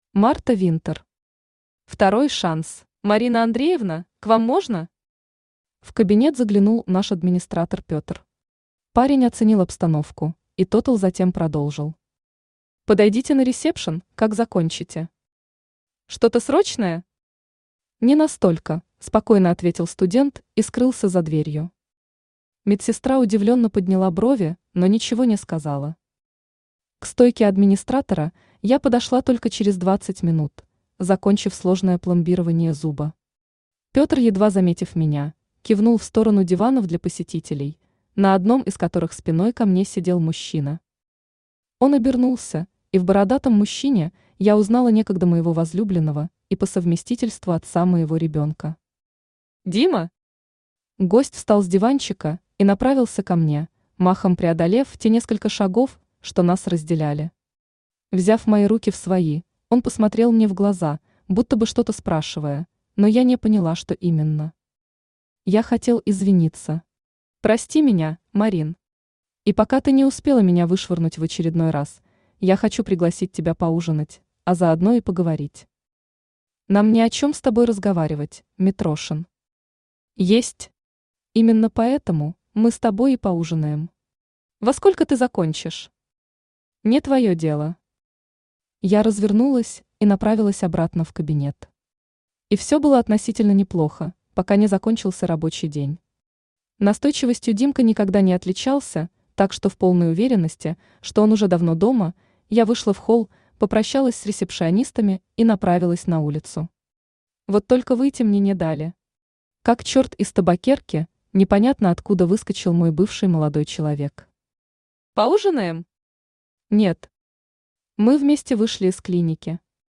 Aудиокнига Второй шанс Автор Марта Винтер Читает аудиокнигу Авточтец ЛитРес. Прослушать и бесплатно скачать фрагмент аудиокниги